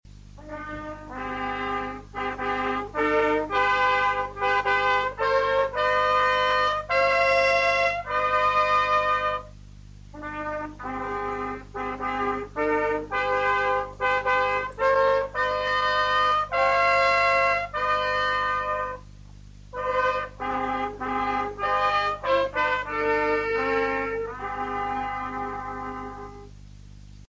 Hier befinden sich die MP3-Dateien, Mitschnitte aus der Radiosendung Aufhorchen in Niederösterreich vom Jänner 2008.
Dritte Fanfare